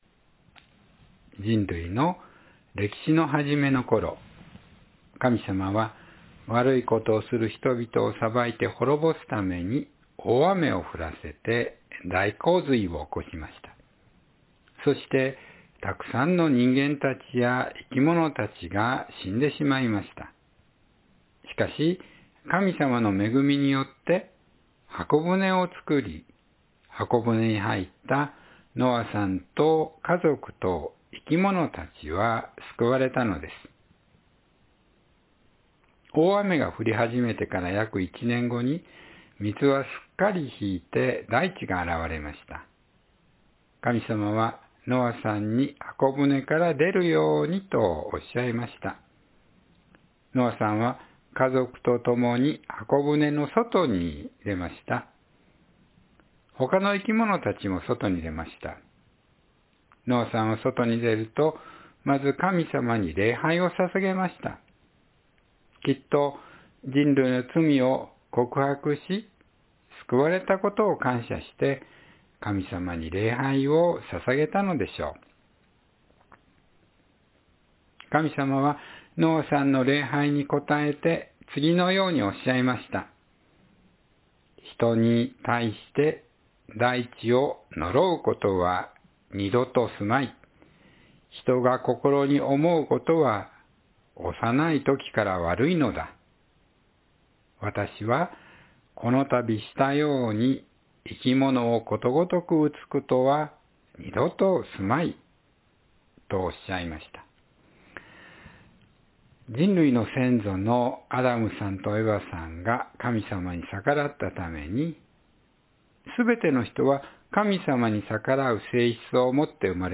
神様とすべての生き物との契約（2026年2月15日・子ども説教）